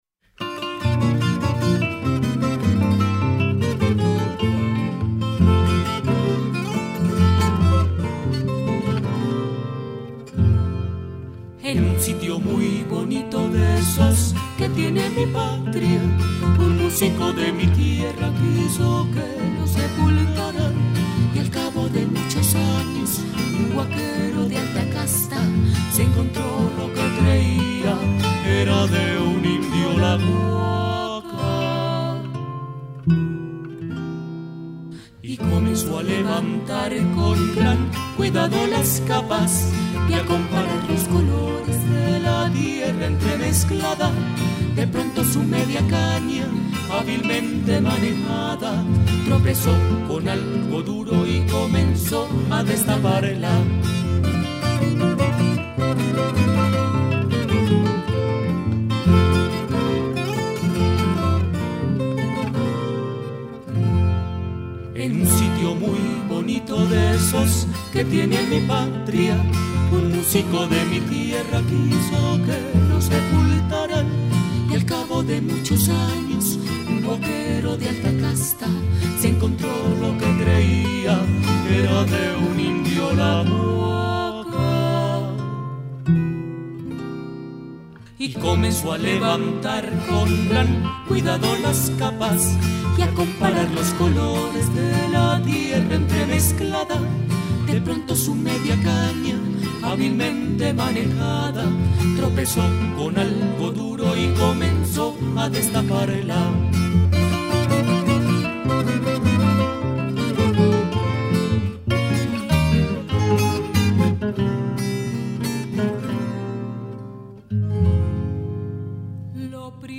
Contralto y Guitarra en Si Bemol
Tenor y Tiple en So bemol
BAMBUCO